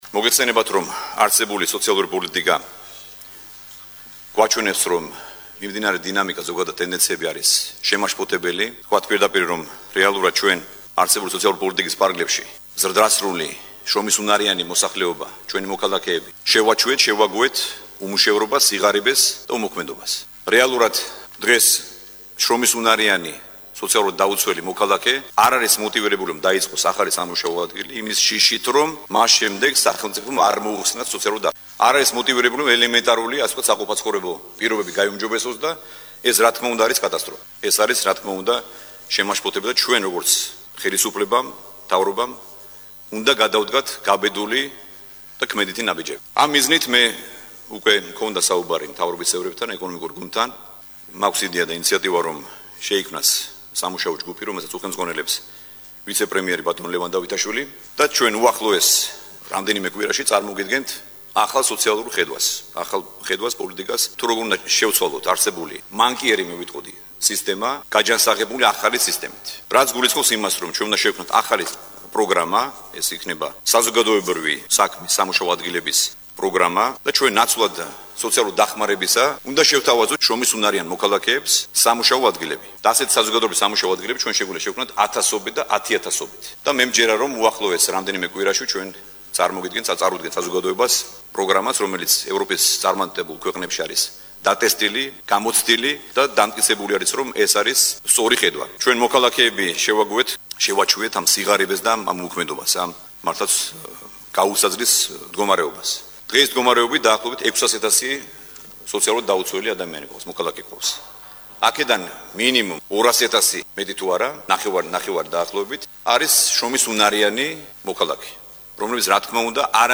პრემიერ-მინისტრი სოციალური პოლიტიკის ფარგლებში ახალ პროგრამას აანონსებს. ამის შესახებ მან მთავრობის სხდომაზე განაცხადა.
ირაკლი ღარიაბშვილის ხმა